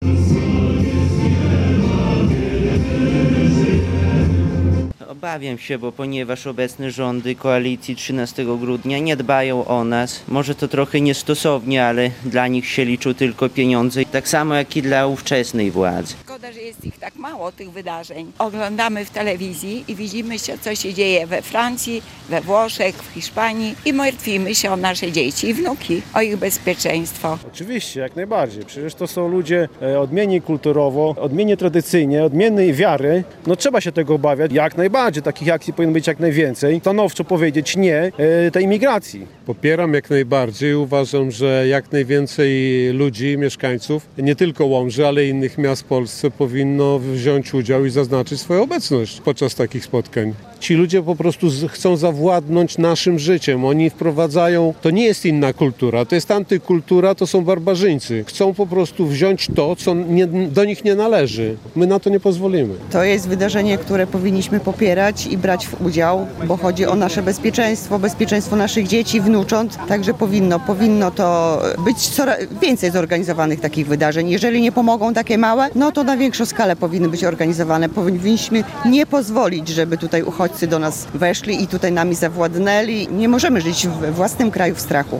Pikieta odbyła się także w Łomży , mieszkańcy zgromadzili się w samo południe na Starym Rynku by wyrazić swój sprzeciw przeciwko nielegalnej migracji. Zapytaliśmy uczestników wydarzenia czy popierają takie pikiety, obawiają się migracji oraz co uważają o takich zgromadzeniach. Zapraszamy do wysłuchania relacji z wydarzenia: